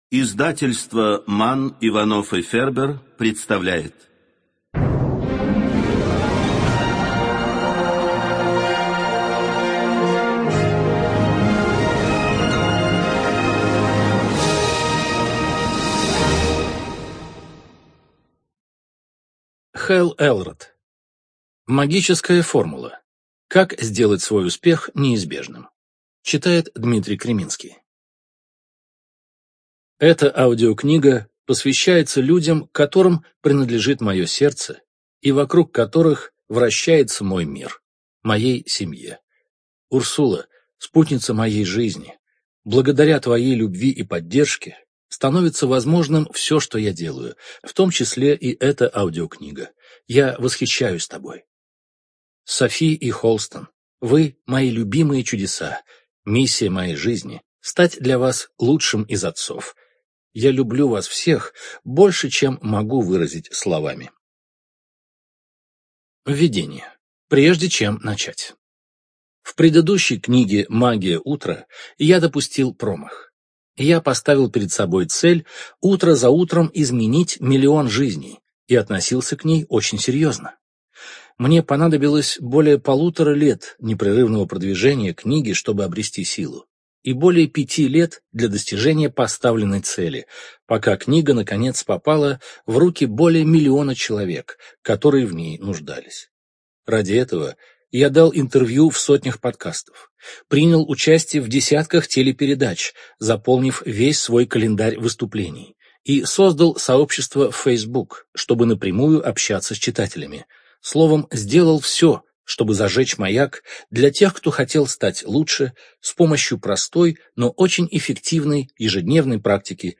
Студия звукозаписиМанн, Иванов и Фербер (МИФ)